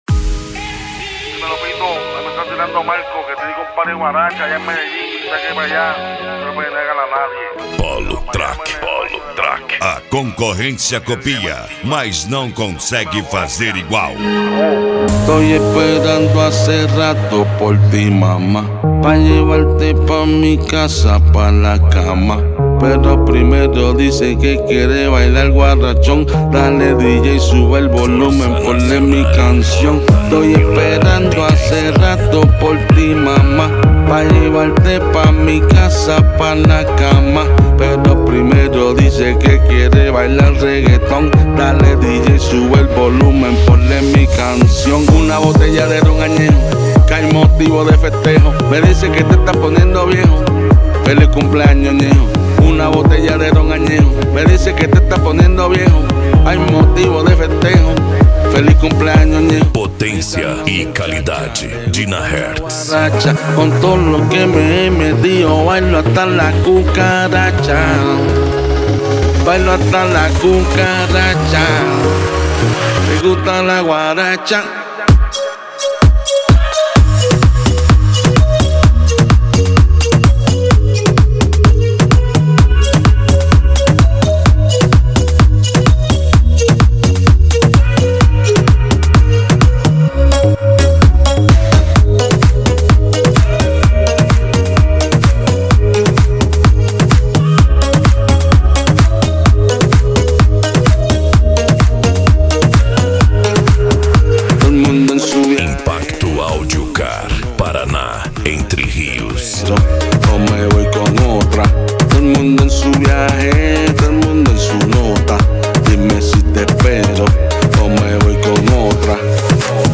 Electro House
Eletronica
Remix